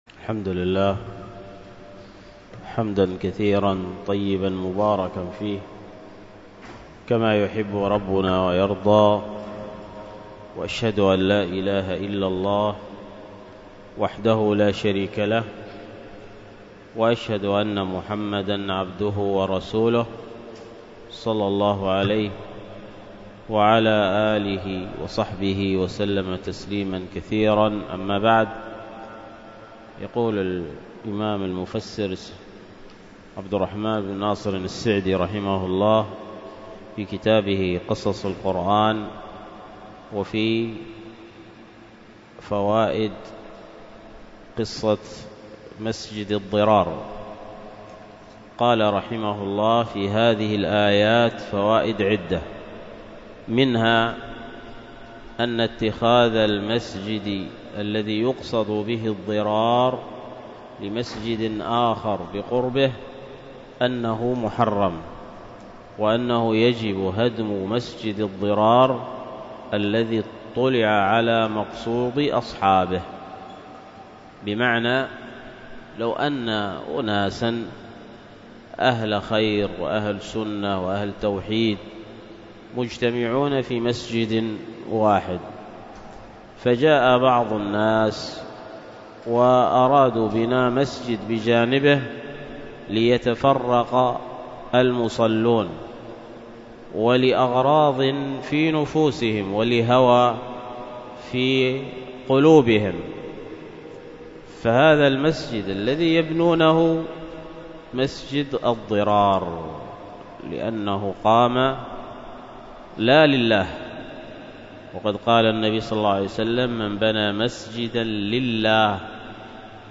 المحاضرة بعنوان فضل مصاحبة الصالحين، والتي كانت بمسجد الصحابة بمنطقة دفيقة بالشحر بين مغرب وعشاء يوم الجمعة 17 ربيع الأولى 1444هـ الموافق 14 أكتوبر 2022م